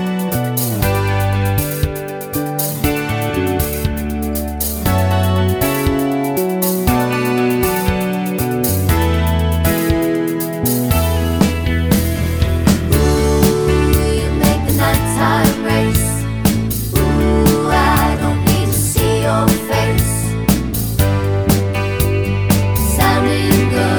no Backing Vocals Pop (1970s) 3:38 Buy £1.50